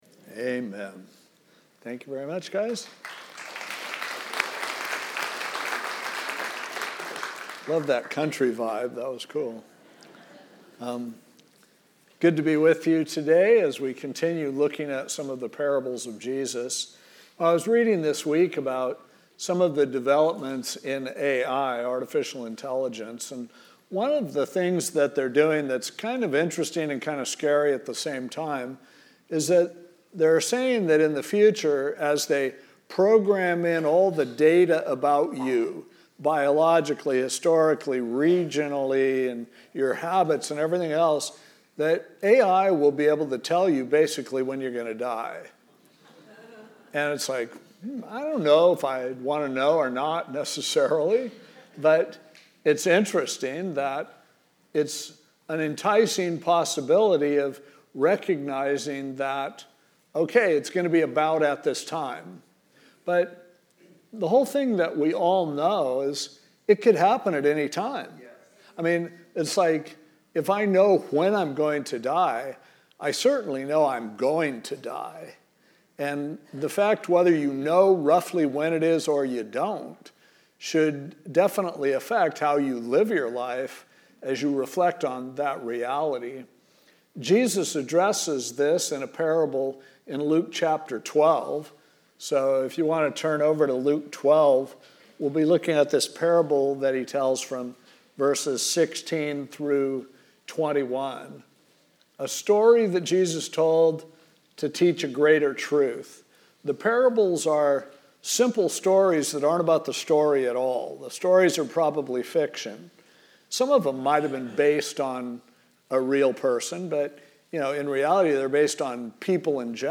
Catch up with recent and past messages